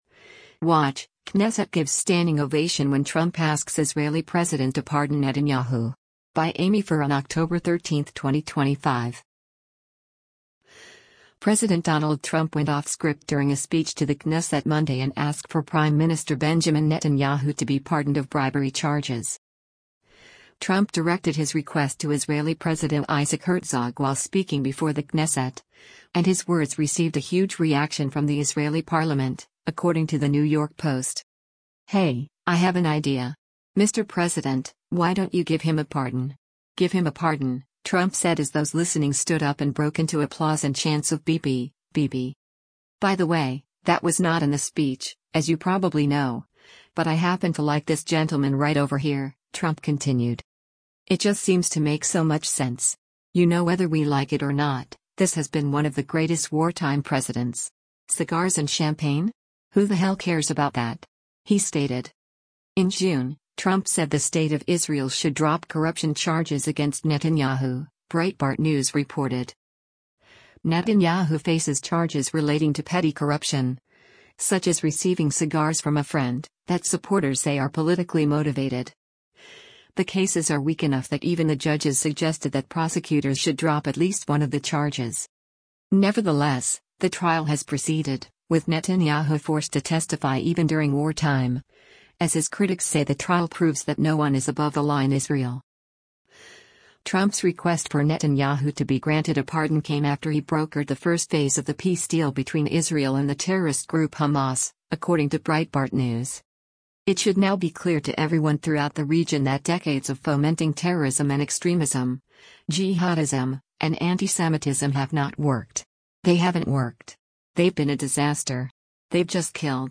WATCH: Knesset Gives Standing Ovation When Trump Asks Israeli President to Pardon Netanyahu
President Donald Trump went off-script during a speech to the Knesset Monday and asked for Prime Minister Benjamin Netanyahu to be pardoned of bribery charges.
“Hey, I have an idea. Mr. President, why don’t you give him a pardon? Give him a pardon,” Trump said as those listening stood up and broke into applause and chants of “Bibi, Bibi!’